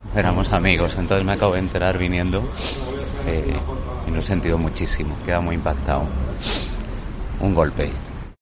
Su fallecimiento ha supuesto para él "un golpe", ha lamentado entre lágrimas